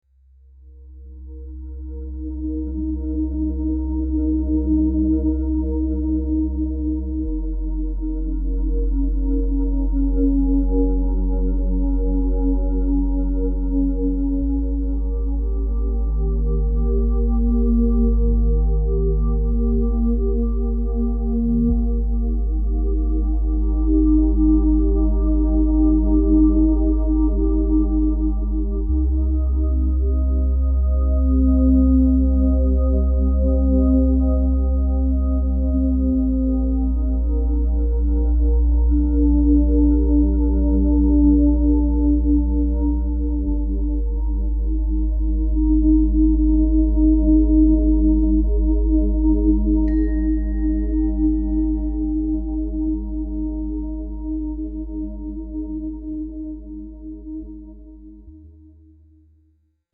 breath-music.mp3